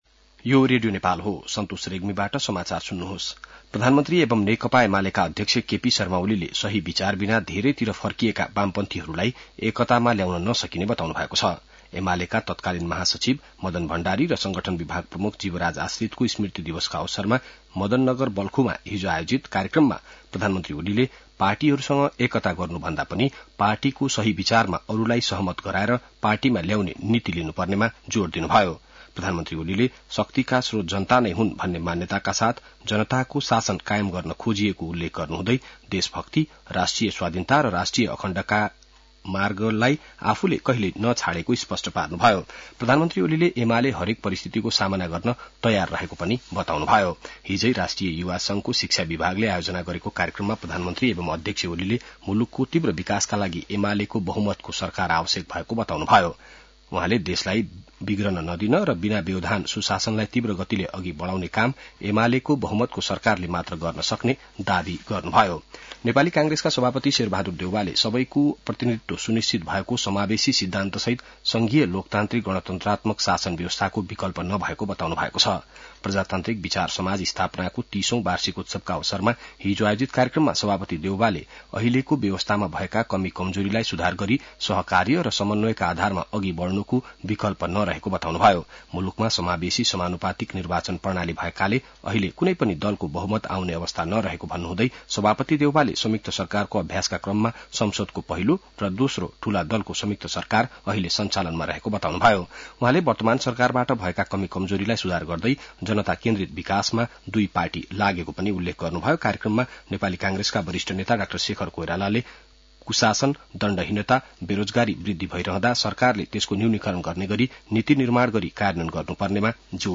बिहान ६ बजेको नेपाली समाचार : ४ जेठ , २०८२